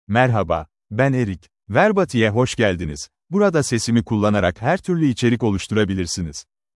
MaleTurkish (Turkey)
EricMale Turkish AI voice
Voice sample
Listen to Eric's male Turkish voice.
Male
Eric delivers clear pronunciation with authentic Turkey Turkish intonation, making your content sound professionally produced.